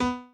b_pianochord_v100l16o4b.ogg